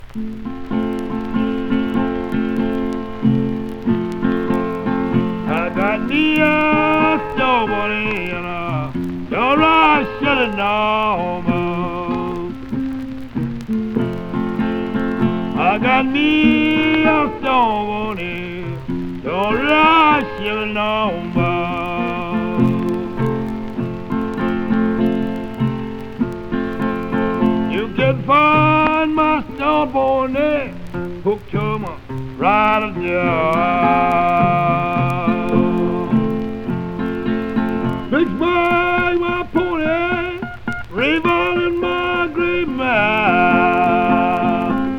Blues　USA　12inchレコード　33rpm　Mono